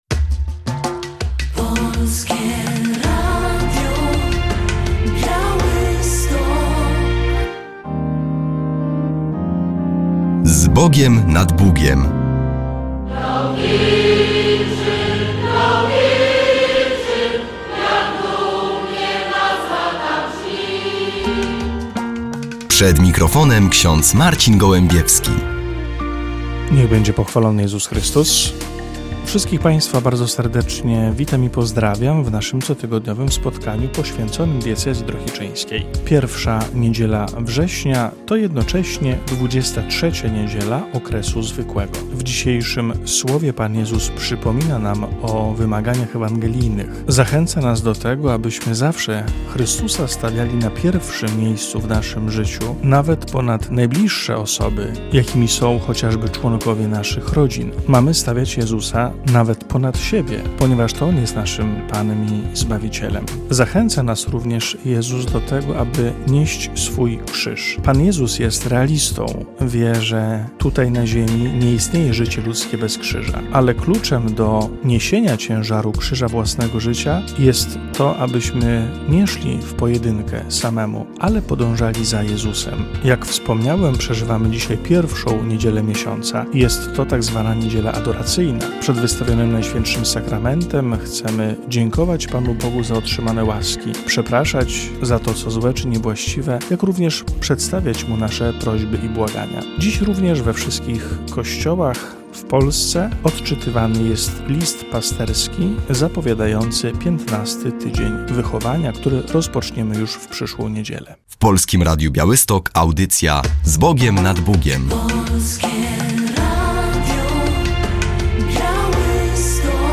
W audycji relacja z Wojewódzkiej Inauguracji Roku Szkolnego w Siemiatyczach.